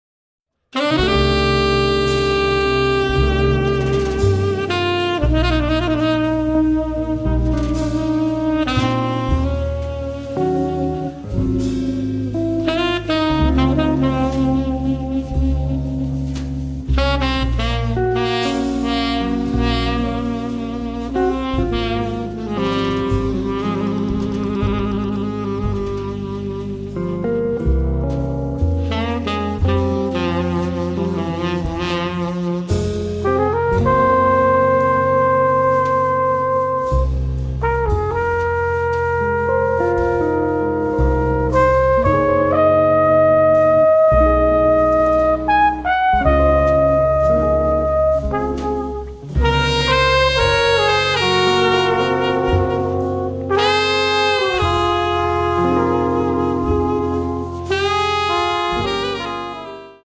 tromba e filicorno